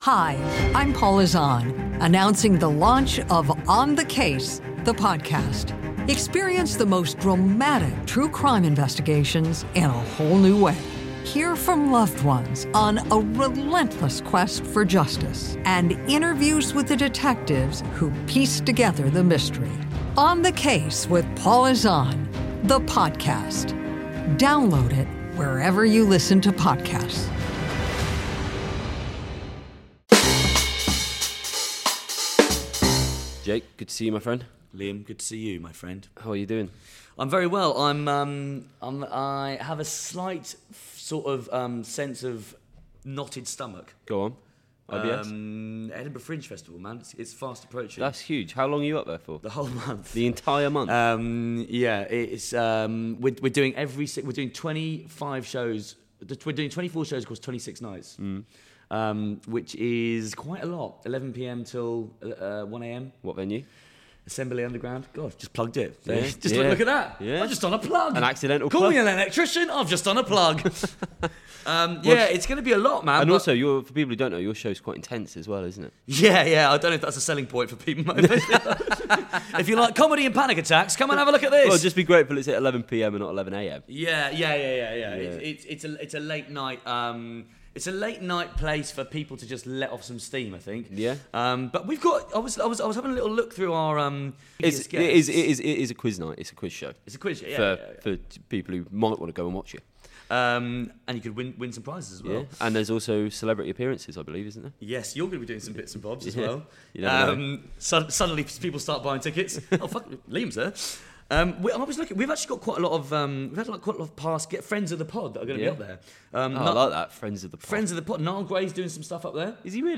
With the help of a different special guest each week, you can expect gags, geekery and glorious nonsense.